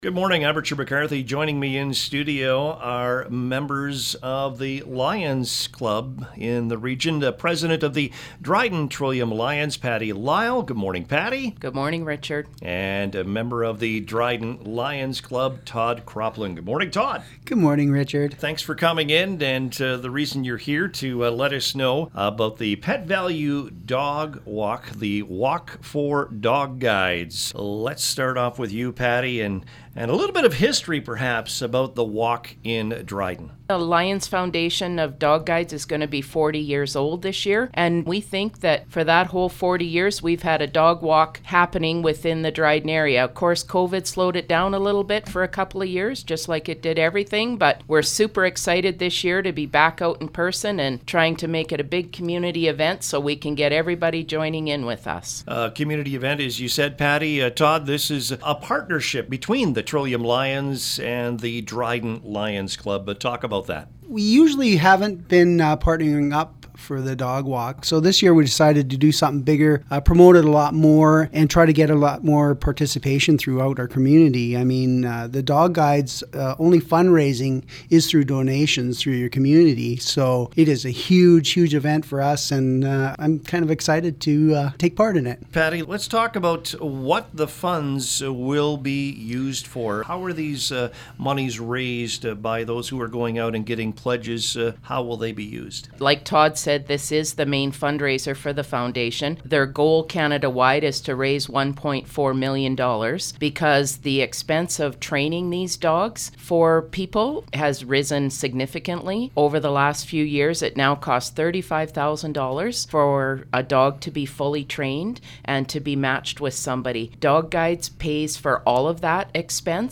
Here is the interview from this morning: